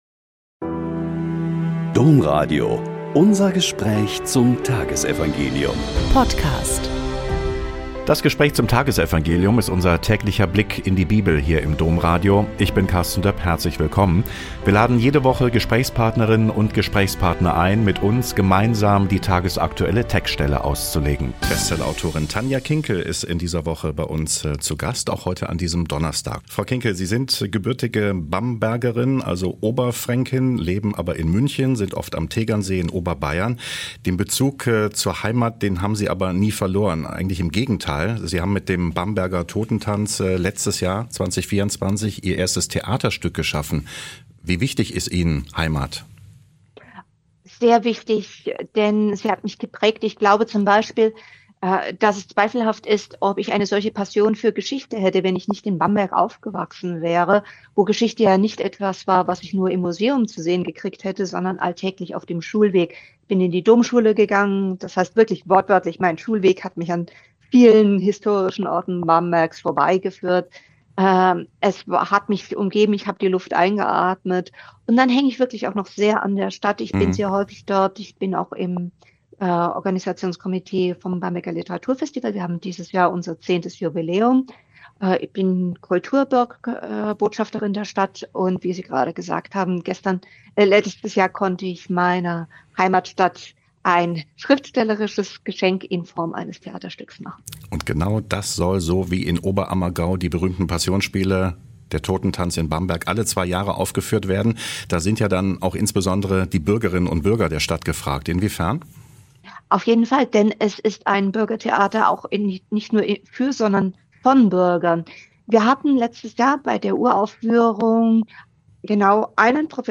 Joh 1,19-28 - Gespräch mit Tanja Kinkel ~ Blick in die Bibel Podcast